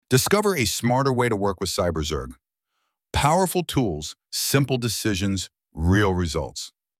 Для генерации мы применили ИИ-голос Алекс (Top Diction) – синтезатор речи с выраженной рекламной подачей.
ИИ-начитка рекламного слогана звучит как полноценная студийная рекламная озвучка. Мы услышали сильный голос с четкой артикуляцией и умеренным темпом.